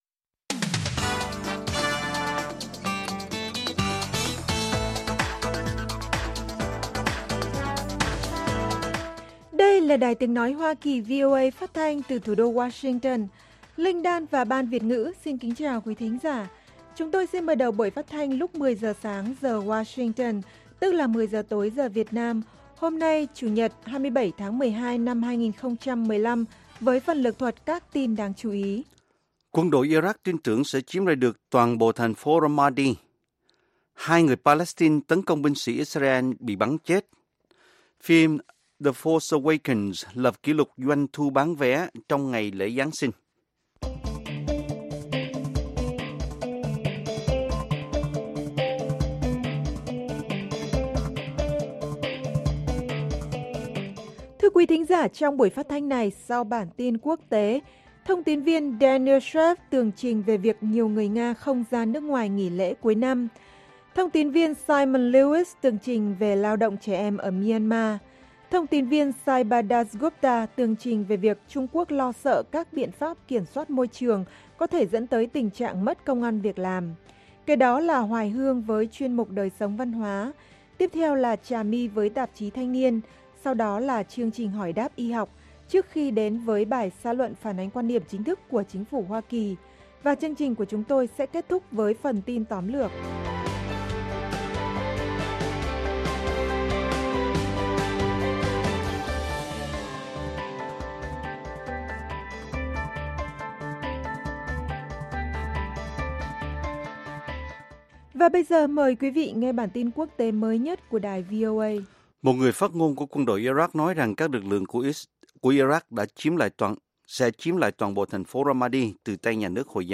Các bài phỏng vấn, tường trình của các phóng viên VOA về các vấn đề liên quan đến Việt Nam và quốc tế, và các bài học tiếng Anh.